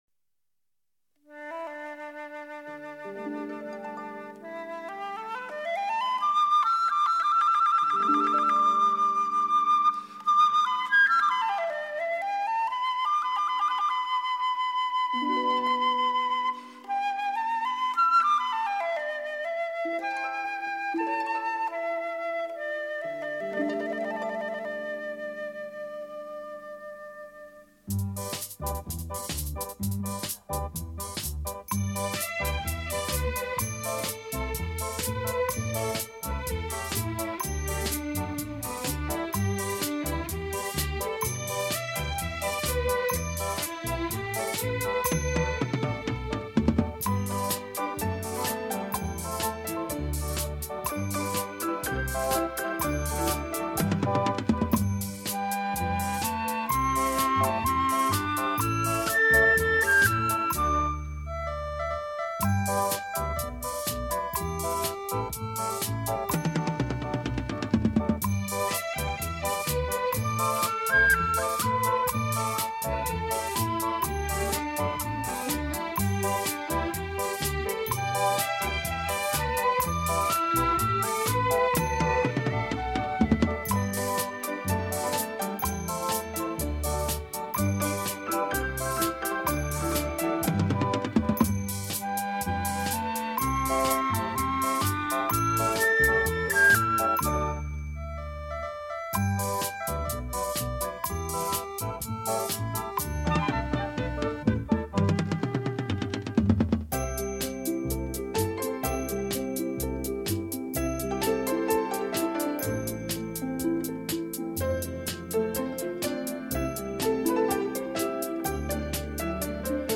轻音乐，是介于古典音乐和流行音乐之间的一种通俗音乐形式。
辑诚为中国特色韵味之情调音乐。